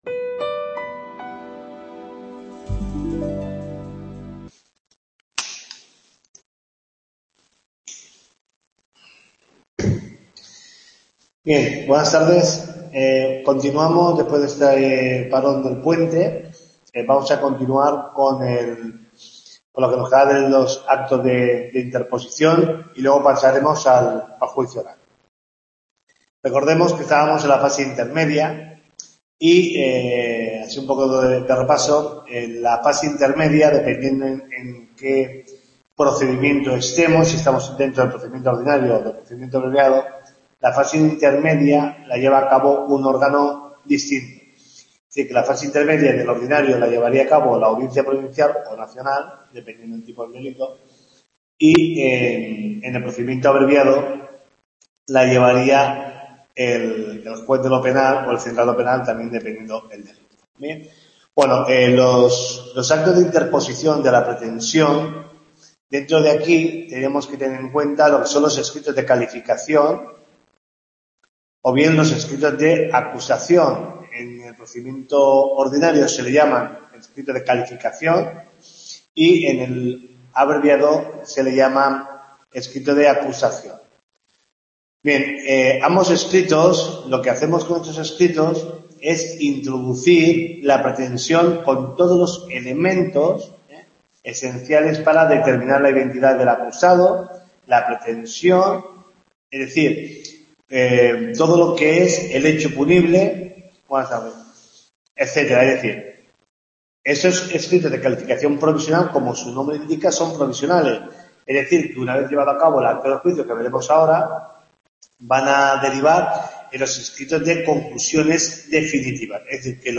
TUTORIA 9